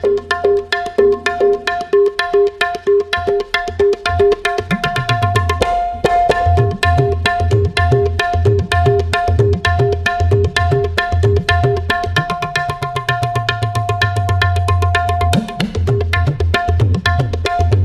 Tabla Ringtones